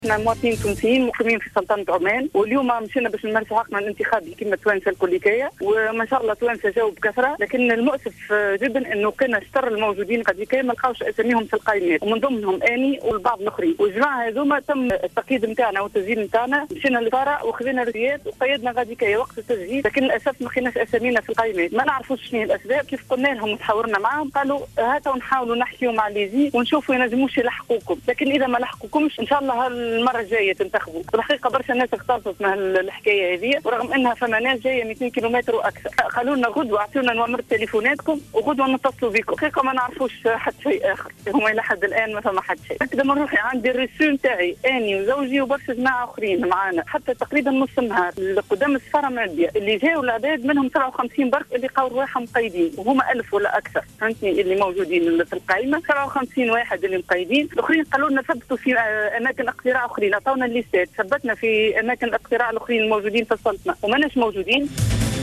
Certains Tunisiens résidant à Oman ont été privés ce vendredi 24 octobre 2014 de voter, a rapporté une citoyenne tunisienne dans une déclaration accordée à Jawhara FM.